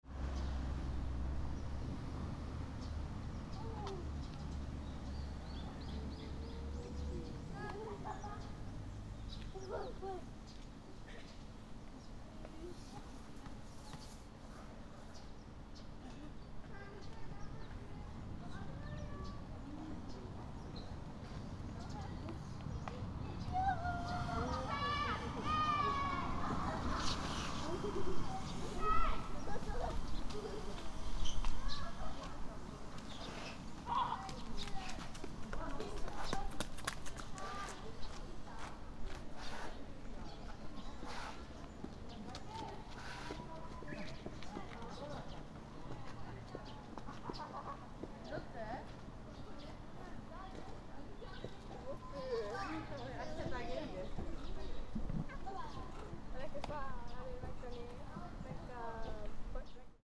Fukushima Soundscape: Mt. Shinobu
Mt. Shinobu Park
In fresh greenery, several families and groups of high school students were playing cheerfully in Mt. Shinobu Park.
Wild birds were twittering ceaselessly, especially a Japanese nightingale singing nearby.